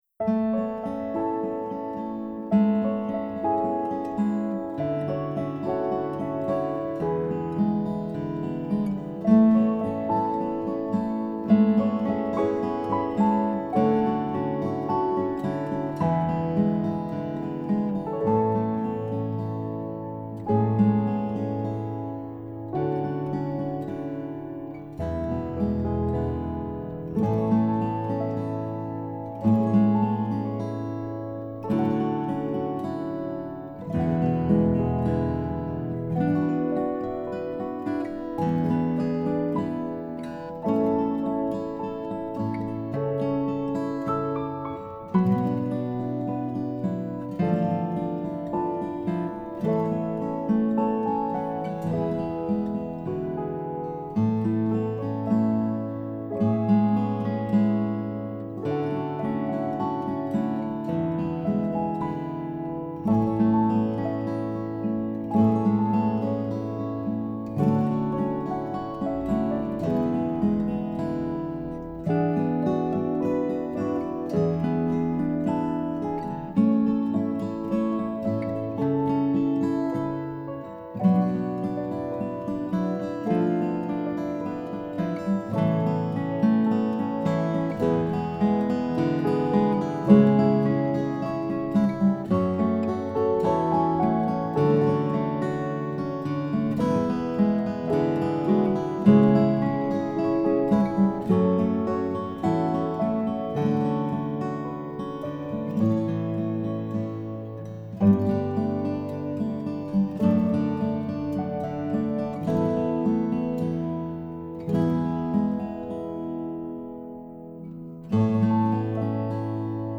Below are some instrumental examples for this song from last year:
in-the-past-piano-guitar-9-15-19.mp3